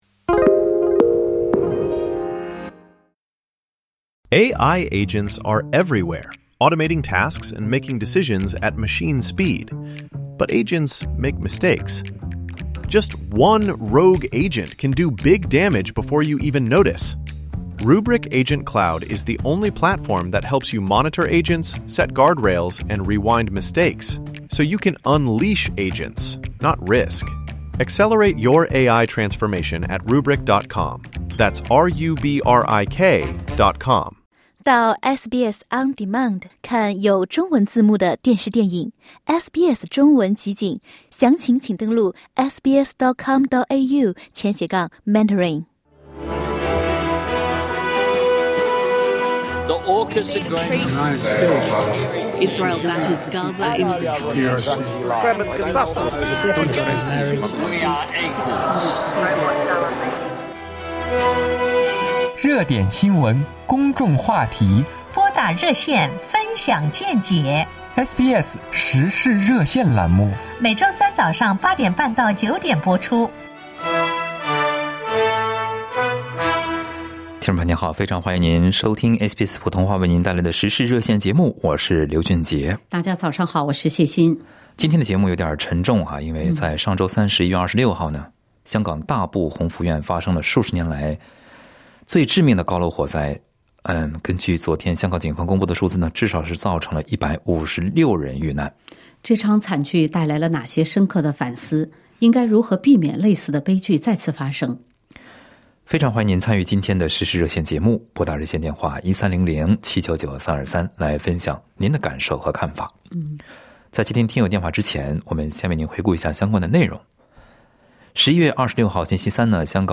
在本期《时事热线》节目中，听友们就这场惨剧分享了各自的感受的看法。